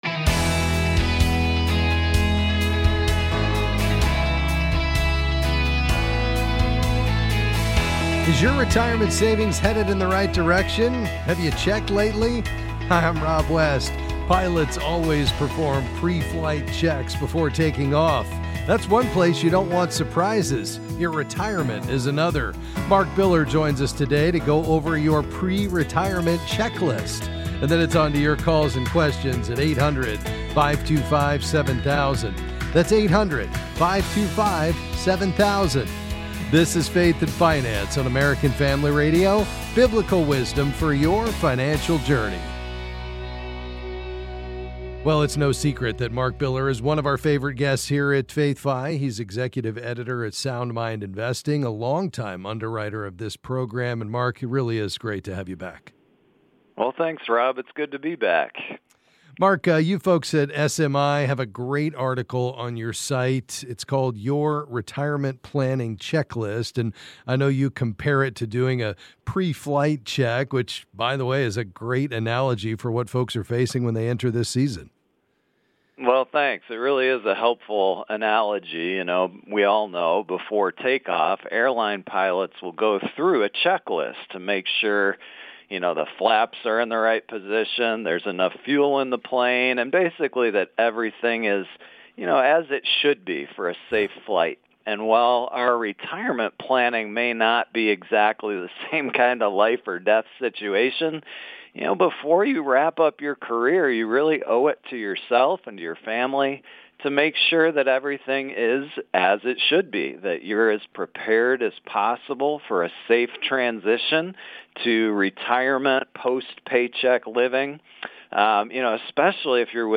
Then they answer your calls and questions about investing.